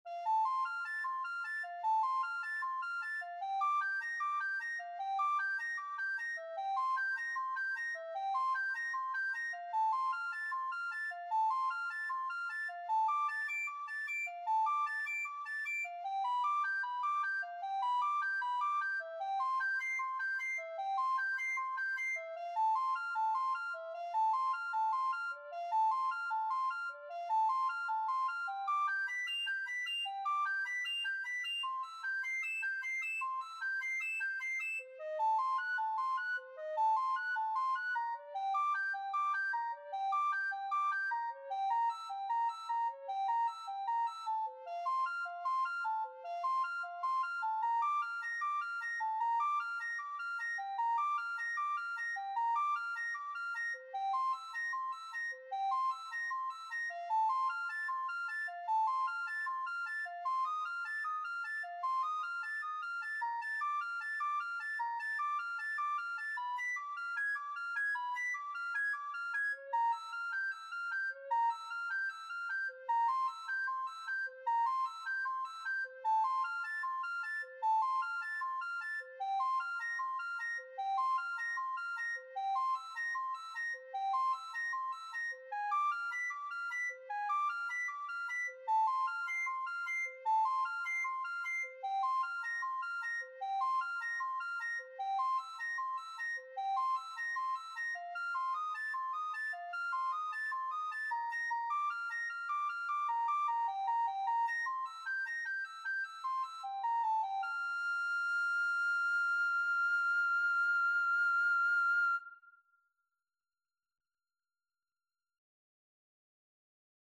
Free Sheet music for Soprano (Descant) Recorder
F major (Sounding Pitch) (View more F major Music for Recorder )
4/4 (View more 4/4 Music)
Moderato =76
C6-F8
Classical (View more Classical Recorder Music)
prelude1_REC.mp3